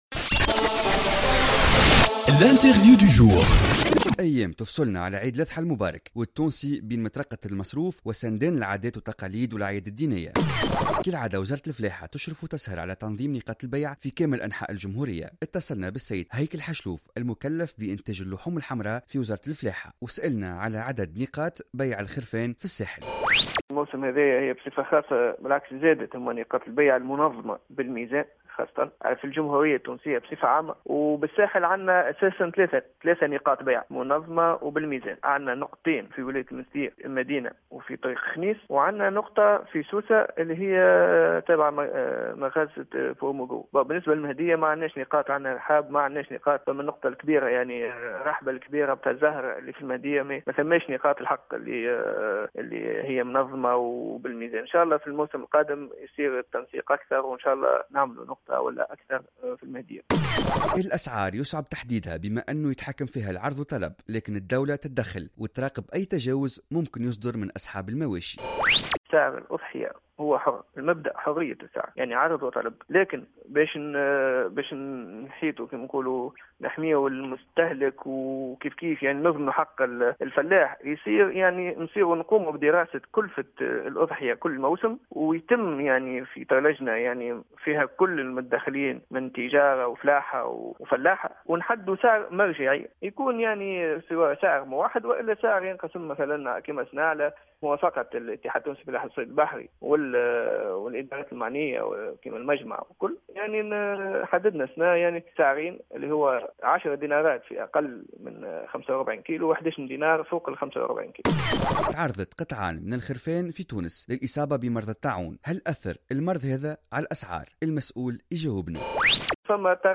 وأفاد في تصريح لبرنامج "صباح الورد" على "الجوهرة أف أم" بأن نقاط الخرفان الثلاث تتمثل في نقطتي المنستير وطريق خنيس ونقطة "بروموغرو" في سوسة فيما سيتم الاقتصار في المهدية على الرحبة لبيع الأضاحي وأساسا رحبة الزهراء.